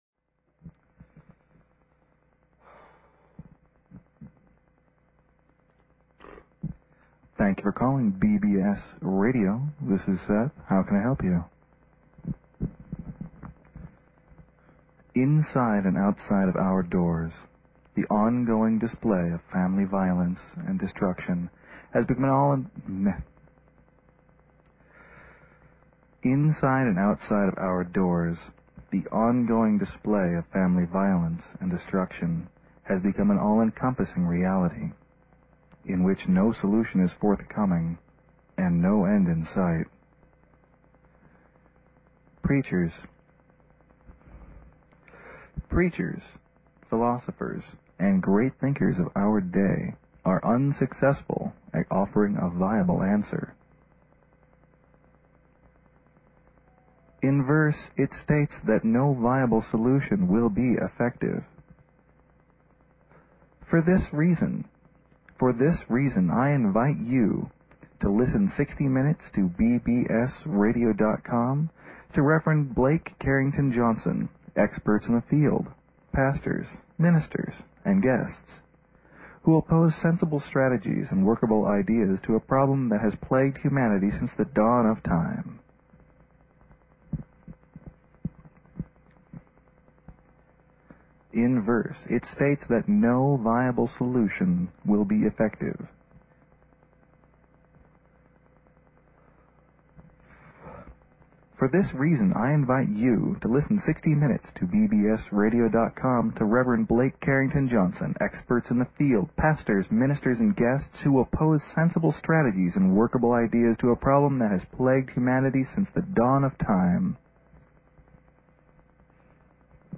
Talk Show Episode, Audio Podcast, Ending_Family_Destruction and Courtesy of BBS Radio on , show guests , about , categorized as